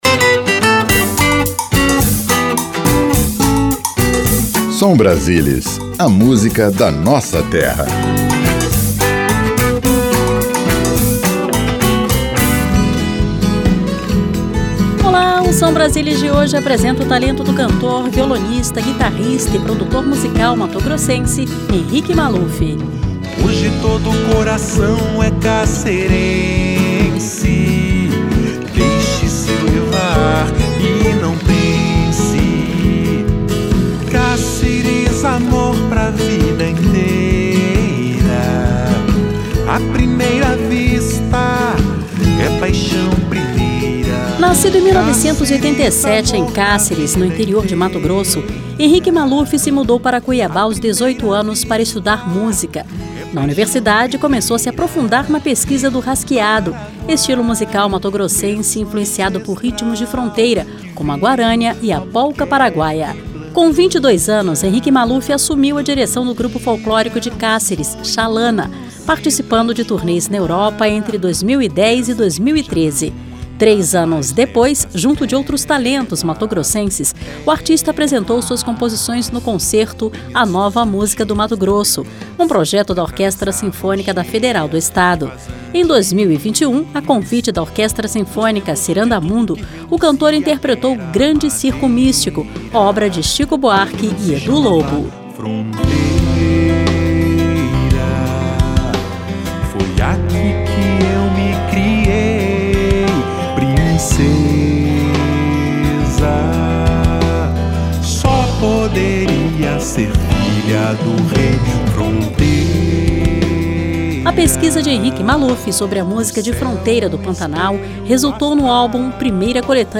Seleção Musical: